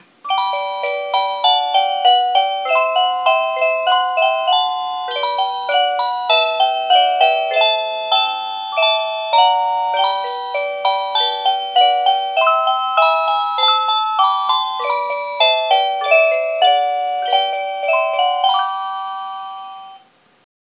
11-Cuckoo-Tune.mp3